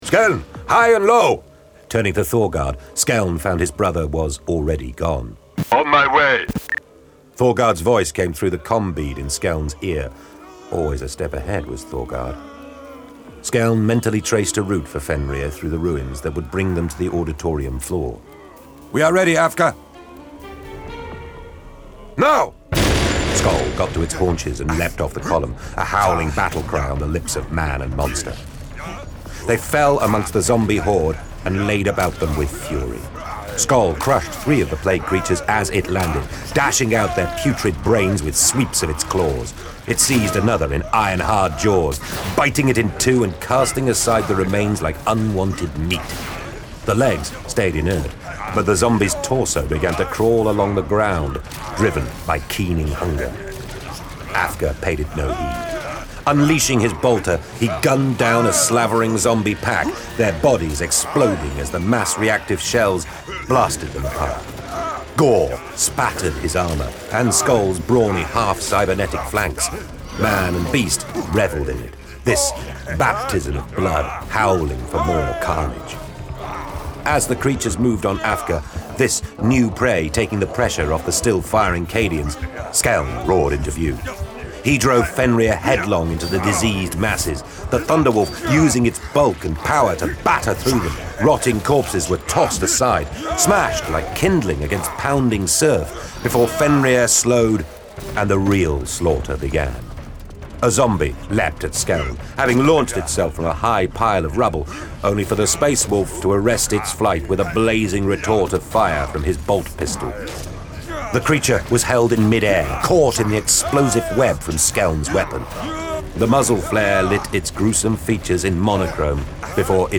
Index of /Games/MothTrove/Black Library/Warhammer 40,000/Audiobooks/Thunder from Fenris